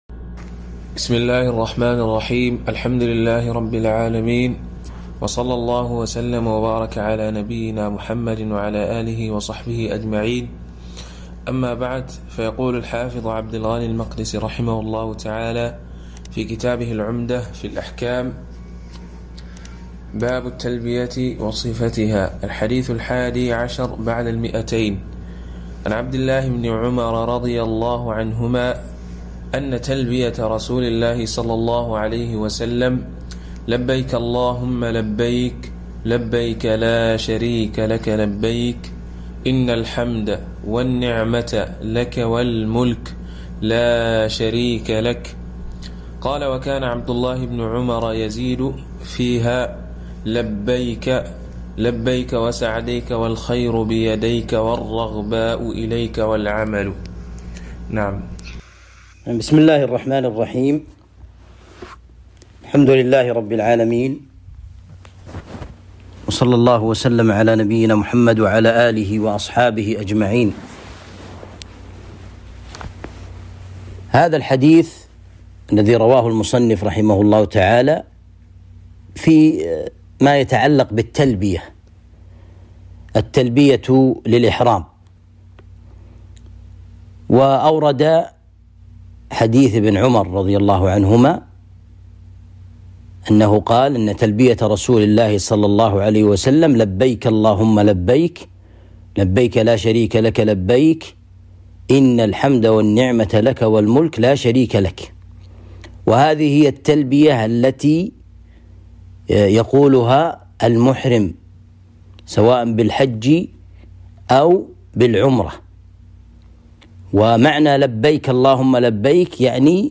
الدروس